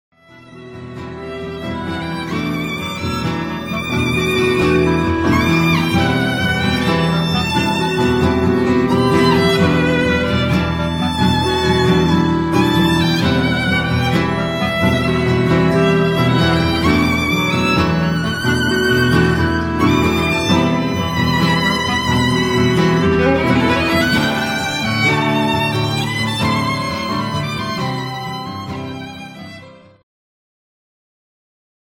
Segment NewAge
Progressive
Acoustic
Instulmental
Symphonic
Violin Future
Wind Instrument Future
Healing
Dramatic, rich and brilliant new acoustic sound!